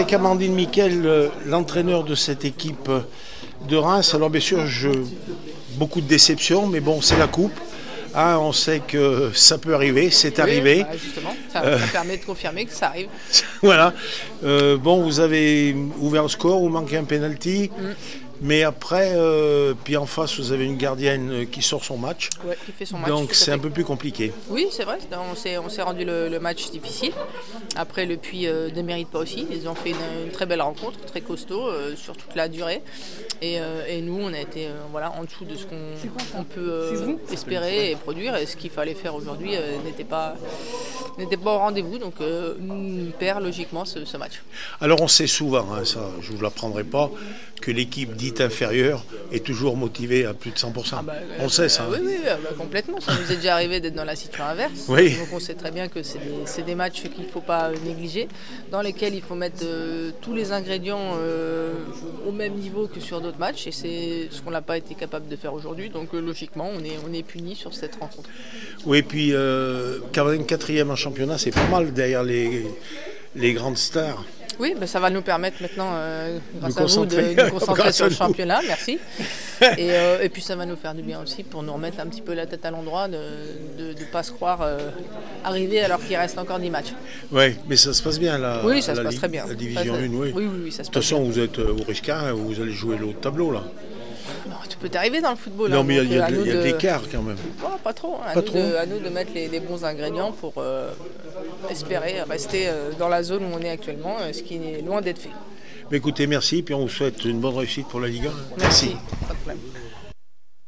29 janvier 2024   1 - Sport, 1 - Vos interviews
coupe de France le puy foot 431-1 Reims réactions après match